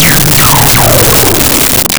Laser24
laser24.wav